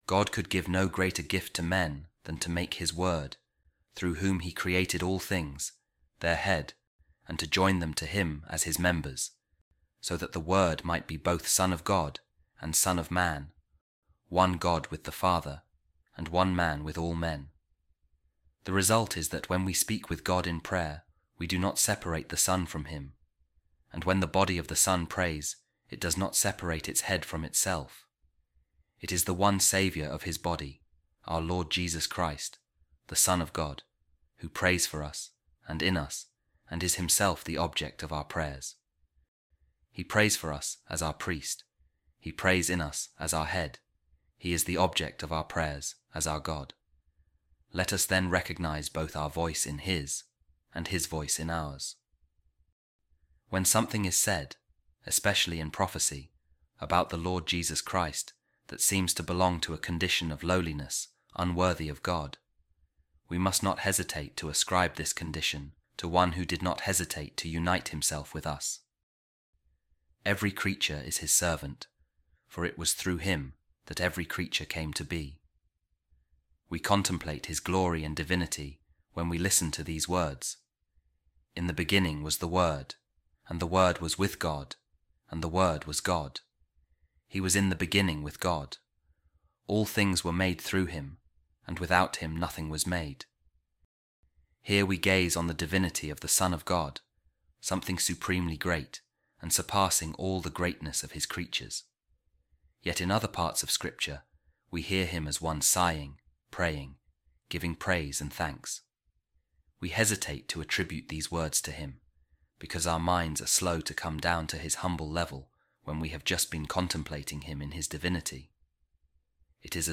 A Reading From The Discourses Of Saint Augustine On The Psalms